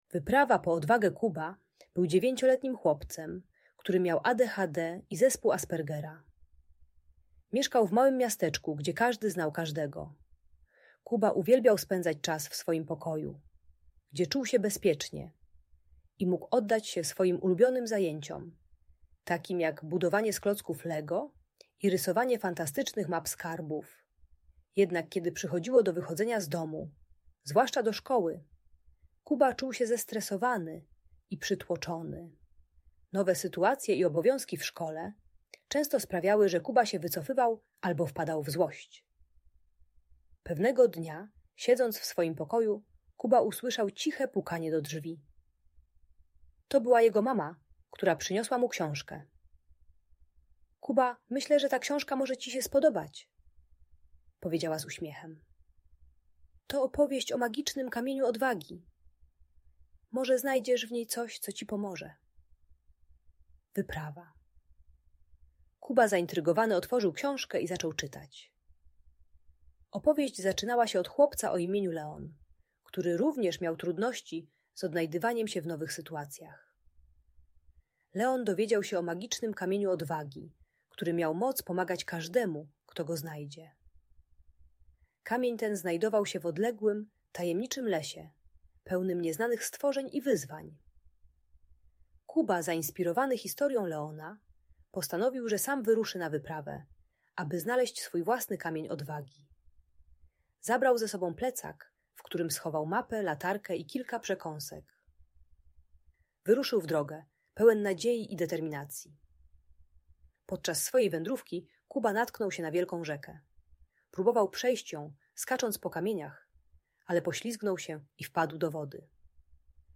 Wyprawa po Odwagę - Audiobajka